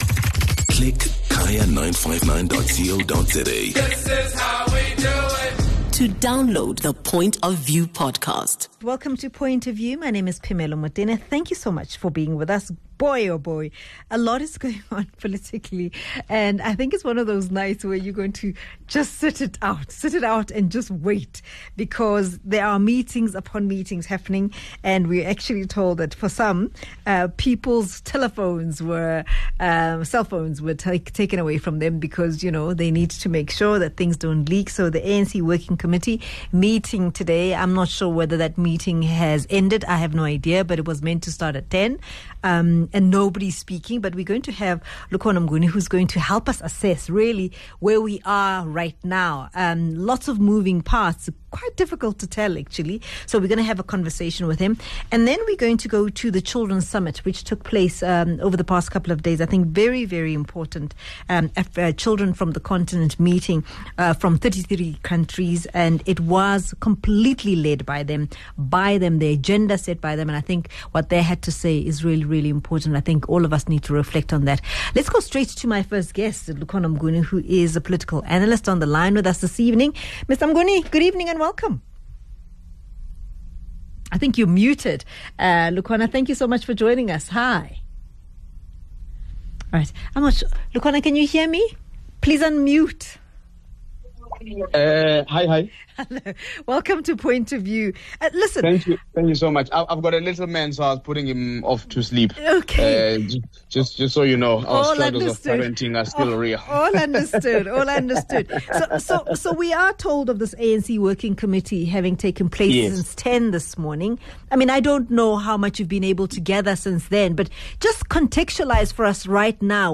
gets analysis on the latest development from political analyst